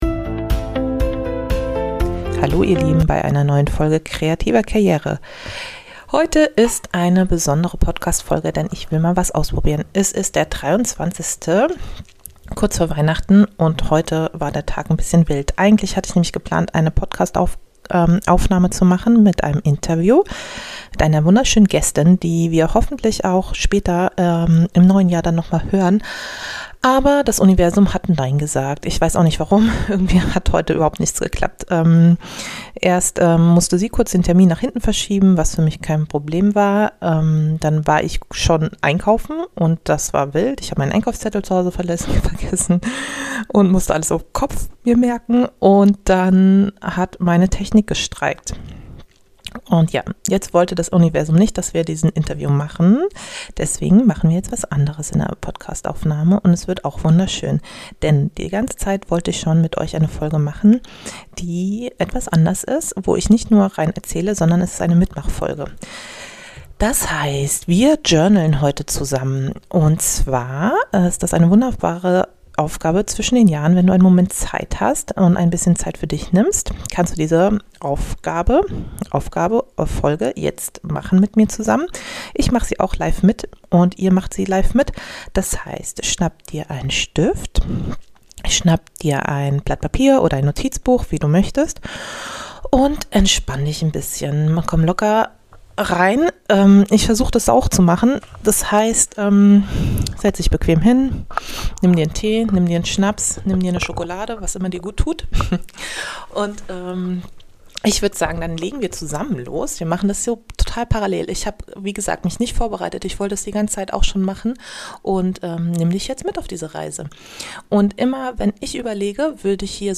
In dieser besonderen Solo-Folge lade ich dich zu einer Live Journaling Session zum Mitmachen ein. Perfekt für die Zeit zwischen den Jahren – wenn es ruhiger wird, der Blick zurück ganz natürlich kommt und das Neue schon leise anklopft.